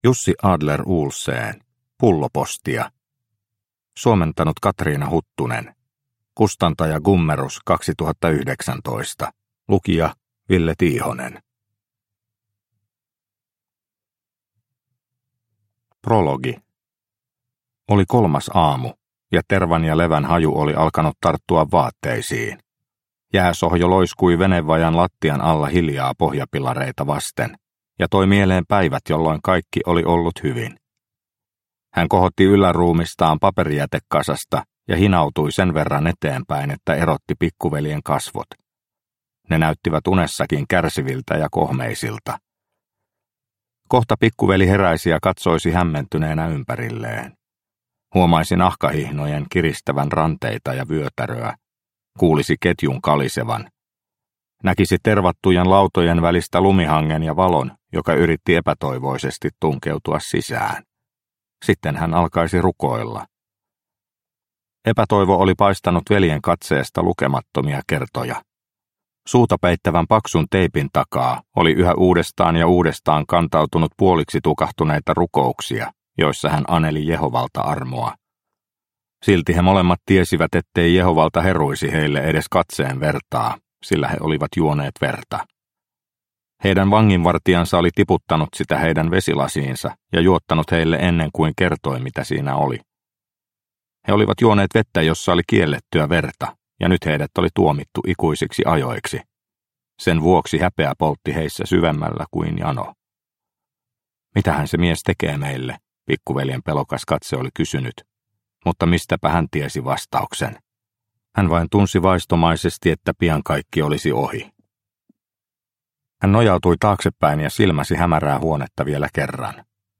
Pullopostia – Ljudbok – Laddas ner